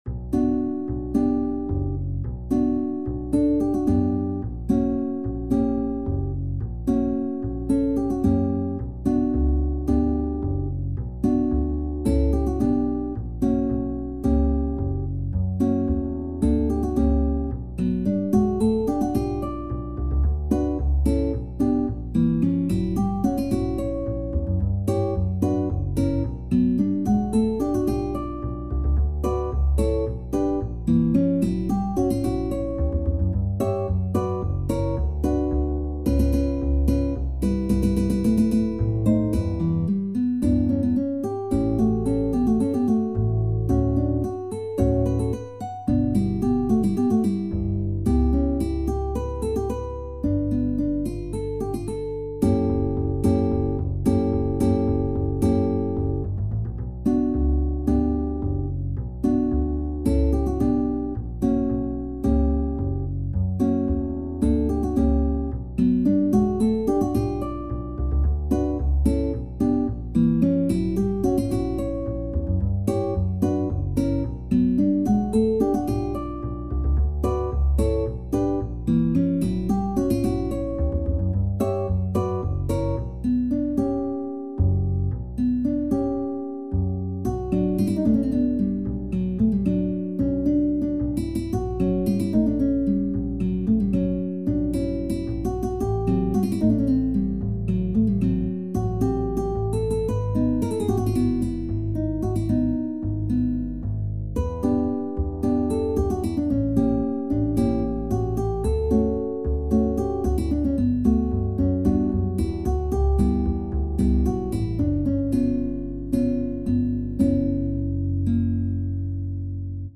SSAB (div.) | SATB (div.)